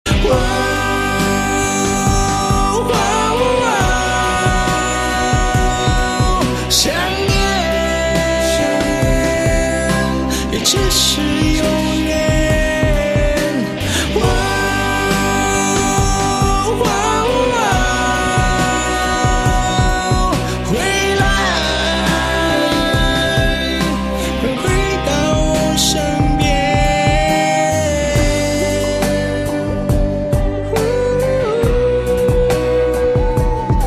M4R铃声, MP3铃声, 华语歌曲 114 首发日期：2018-05-15 14:58 星期二